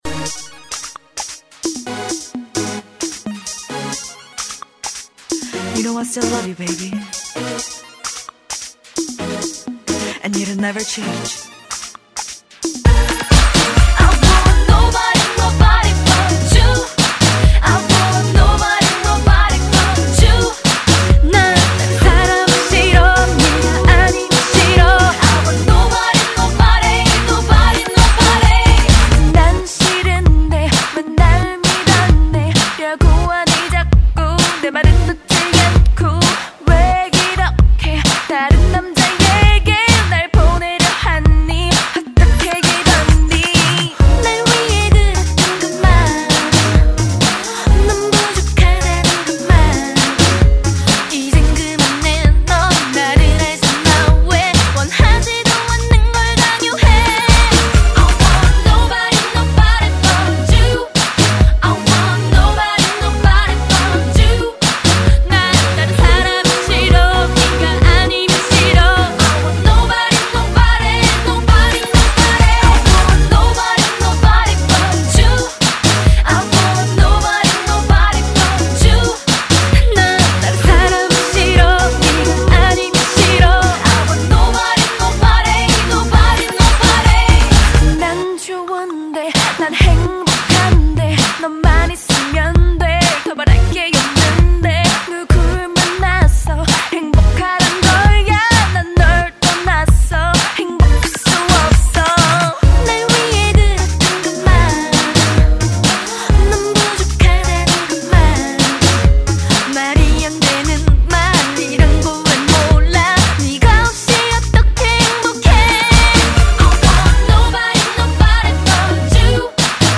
Very catchy song.